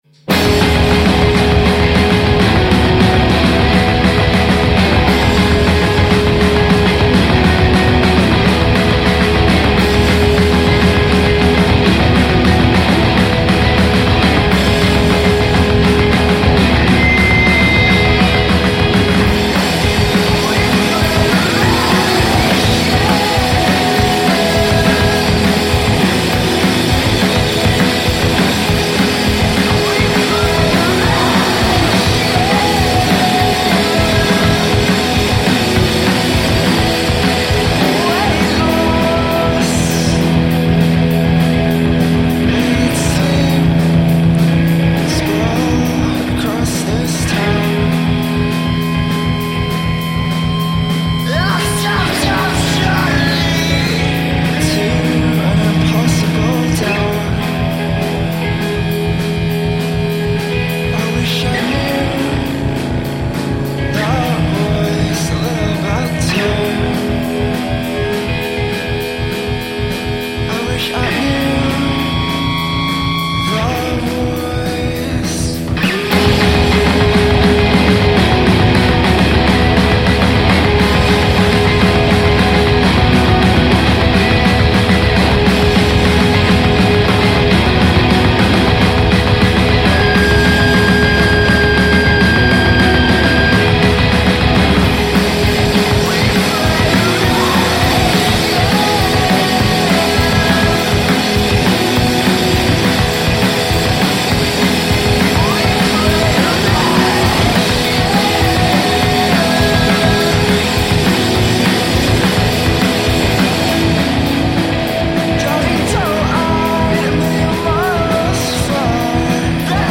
batteria e voce
alla chitarra, definiscono il proprio suono noise-pop